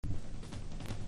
POWER POP# PUNK / HARDCORE# 90’s ROCK# PUNK